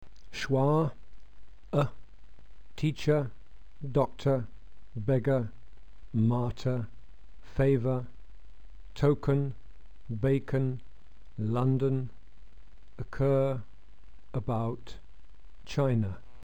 Lax vowels are always short
schwa.mp3